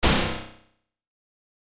アタック 002
シンプル 不快ドコン